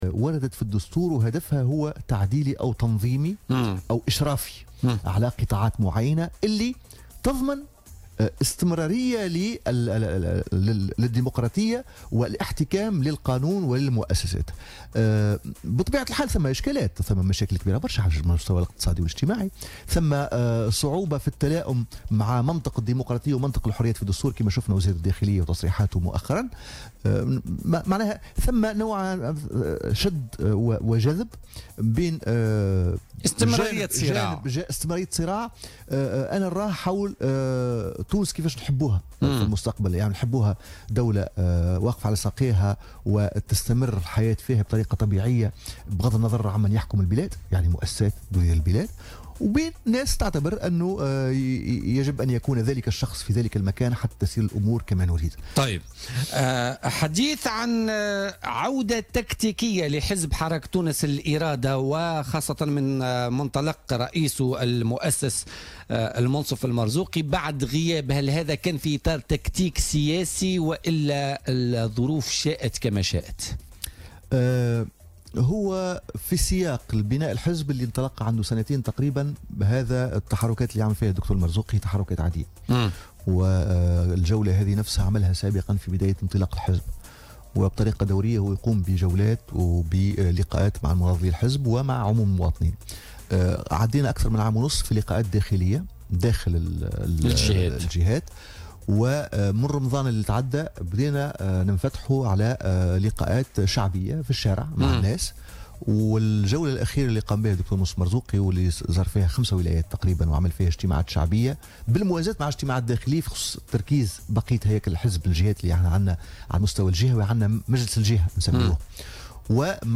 أكد القيادي في حزب حراك تونس الإرادة عدنان منصر ضيف بوليتيكا أن هناك ضغوطات تمارس خاصة على وسائل الإعلام العمومية لمنع استضافة المنصف المرزوقي وقياديي حراك تونس الإرادة ومنع ظهورهم اعلاميا.